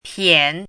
piǎn
拼音： piǎn
pian3.mp3